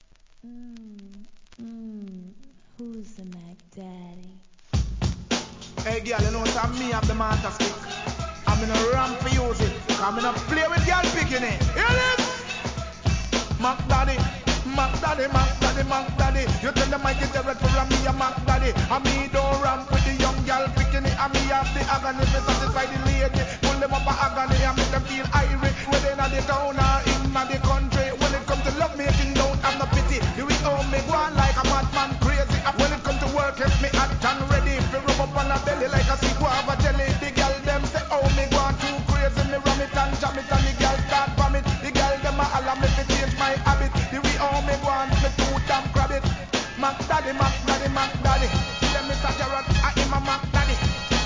REGGAE
人気ラガHIP HOP